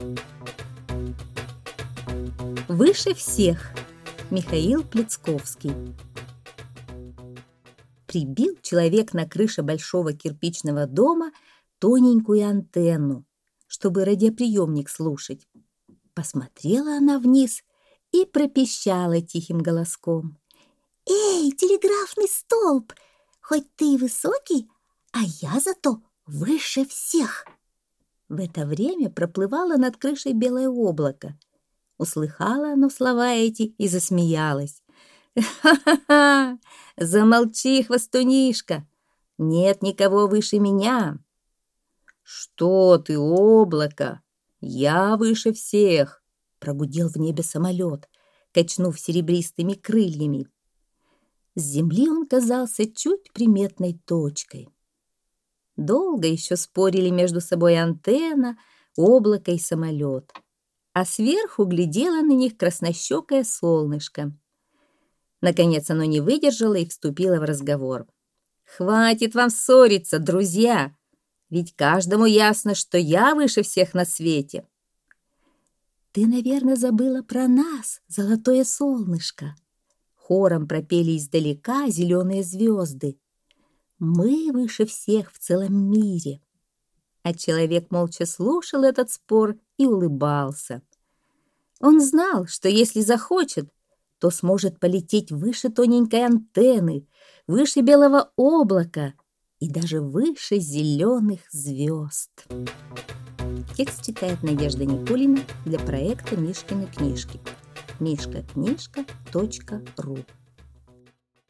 Аудиосказка «Выше всех»